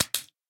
Minecraft Version Minecraft Version latest Latest Release | Latest Snapshot latest / assets / minecraft / sounds / mob / guardian / flop2.ogg Compare With Compare With Latest Release | Latest Snapshot
flop2.ogg